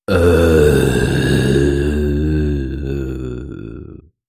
Зомби грезит мозгами